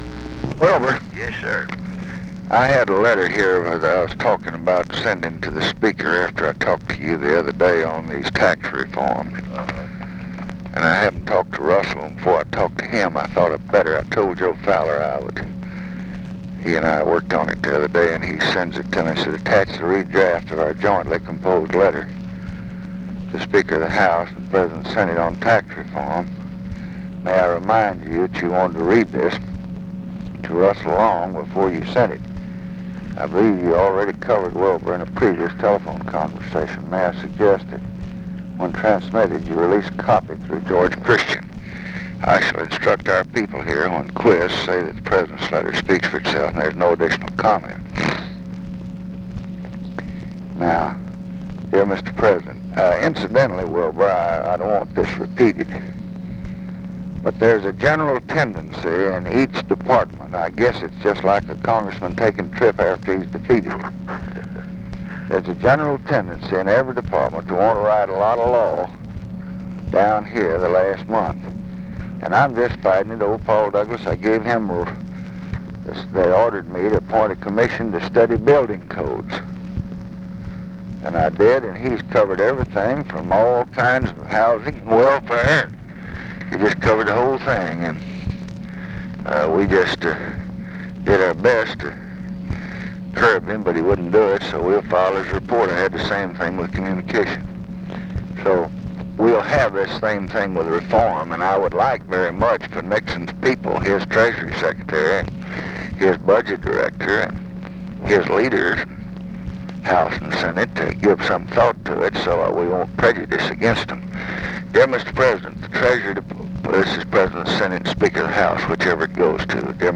Conversation with WILBUR MILLS and OFFICE CONVERSATION, December 16, 1968
Secret White House Tapes